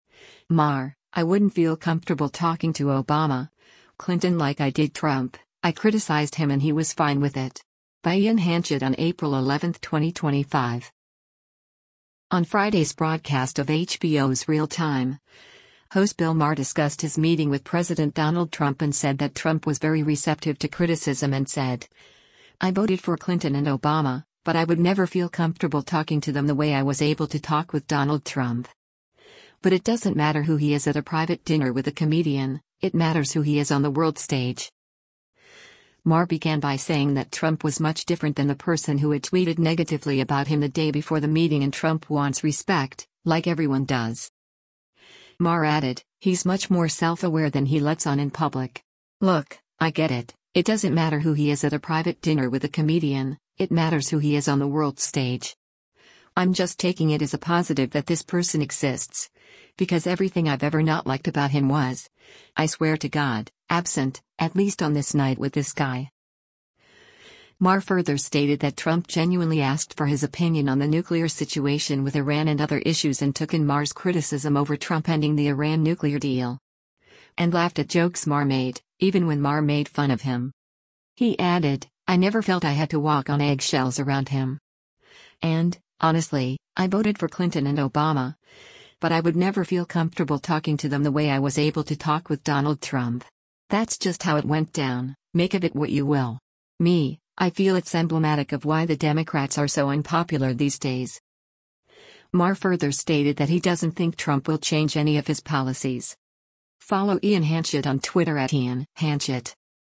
On Friday’s broadcast of HBO’s “Real Time,” host Bill Maher discussed his meeting with President Donald Trump and said that Trump was very receptive to criticism and said, “I voted for Clinton and Obama, but I would never feel comfortable talking to them the way I was able to talk with Donald Trump.” But “it doesn’t matter who he is at a private dinner with a comedian, it matters who he is on the world stage.”